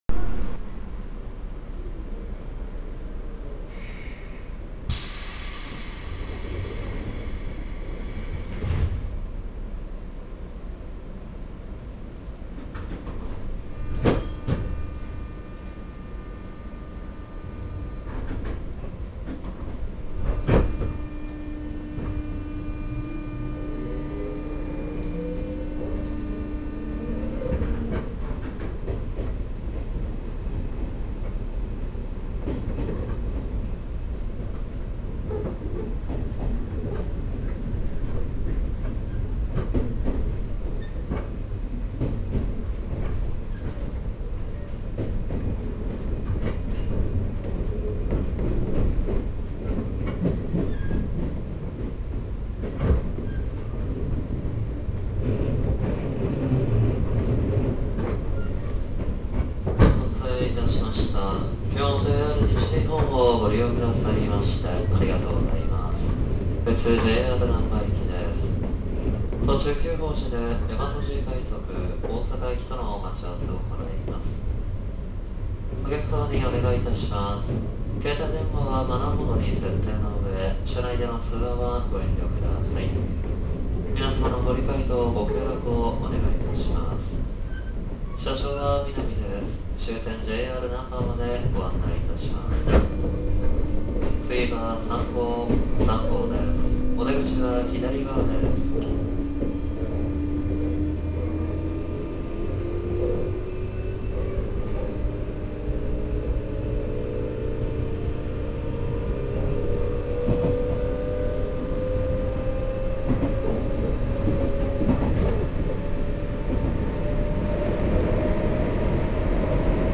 ・201系走行音
【大和路線】王寺→三郷（3分12秒：1.02MB）
走行音は全く変わりません。当たり前のように聞けたこのチョッパ音も、そろそろ先が見えてきた様な気がします。